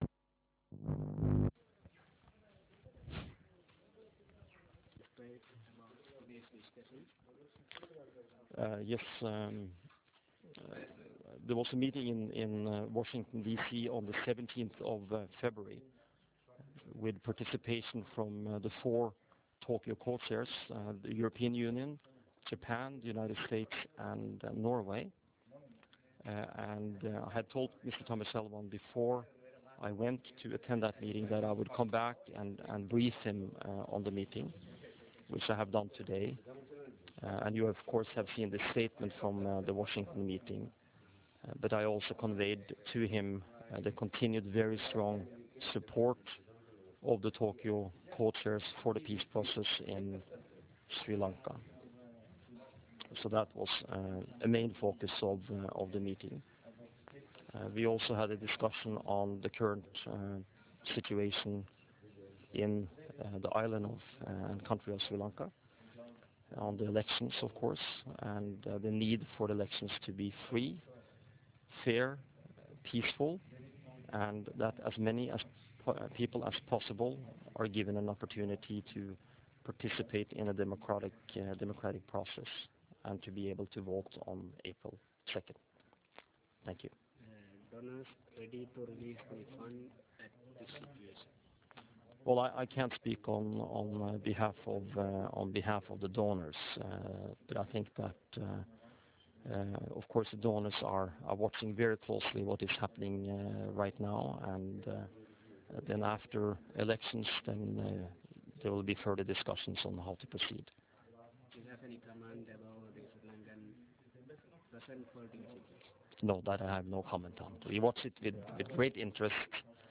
Thamilchelvan (Tamil) talk to journalists